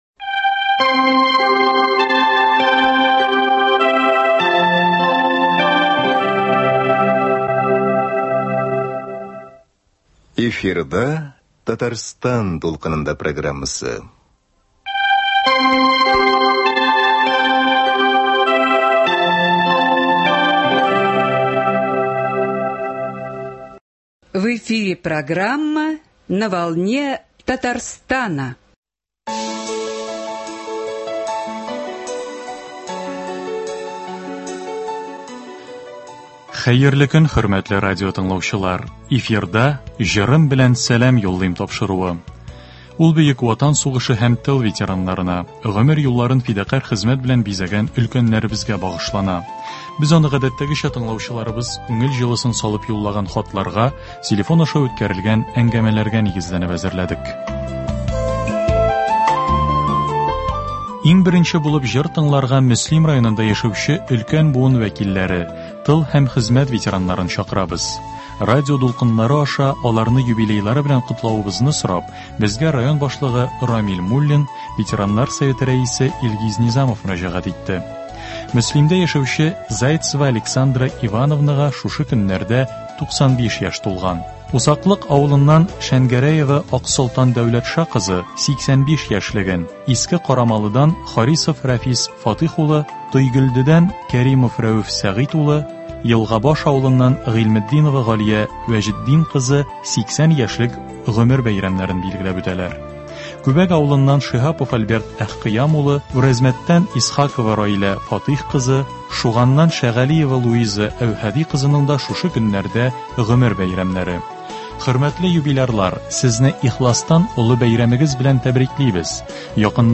музыкаль программасы